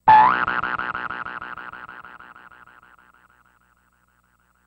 Здесь вы найдете плавные затухания, исчезающие эффекты и другие варианты финальных аудиоштрихов.
Звук завершения ситуации